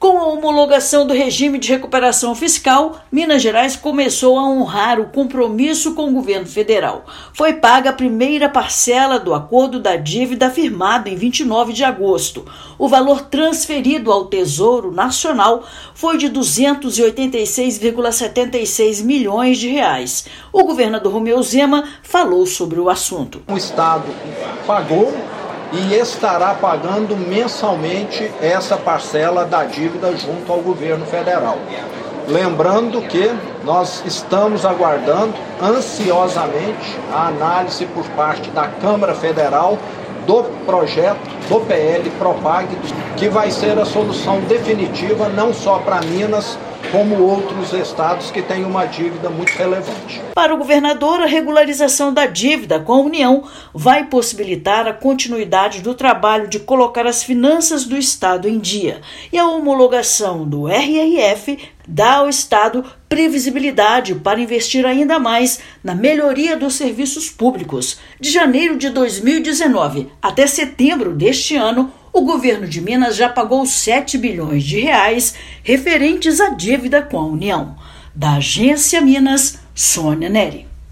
Reposição salarial da inflação, promoções e concursos estão autorizados pelas regras do RRF e servidores não serão afetados. Ouça matéria de rádio.